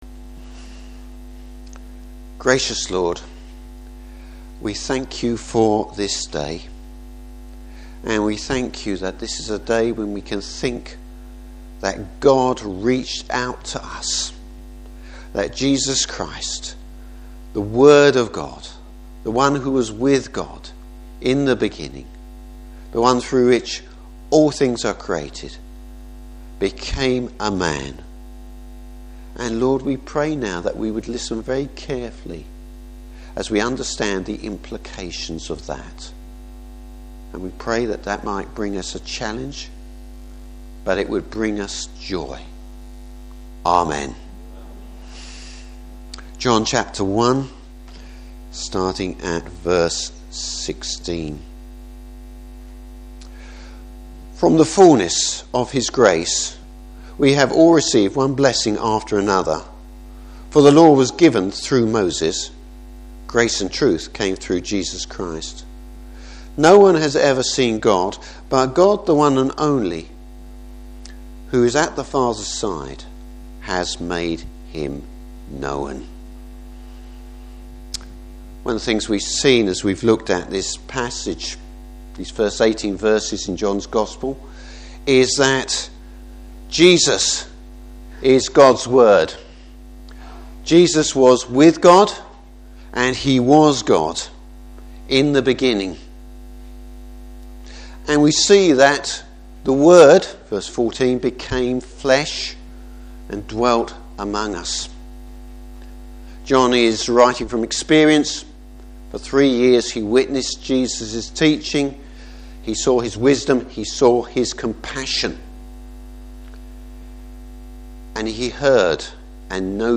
Service Type: Christmas Day Morning Service.